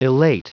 Prononciation du mot elate en anglais (fichier audio)
Prononciation du mot : elate